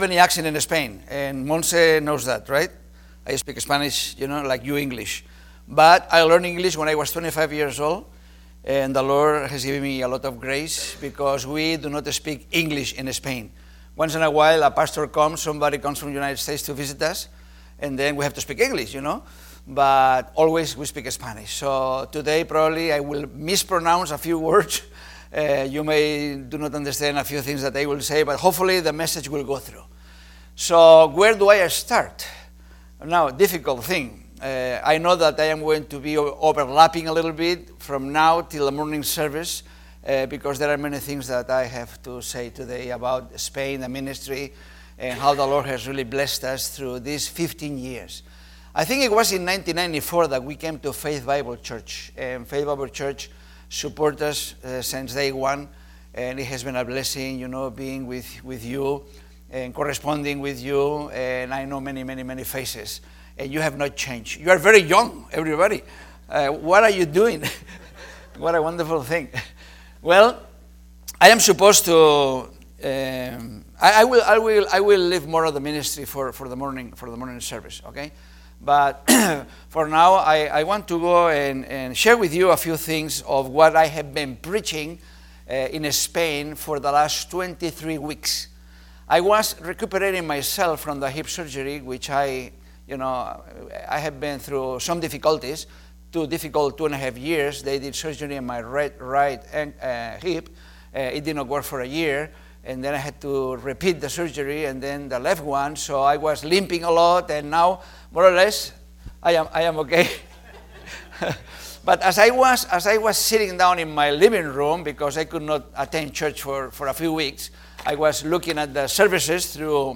Sunday School - Faith Bible Church
Service Type: Sunday School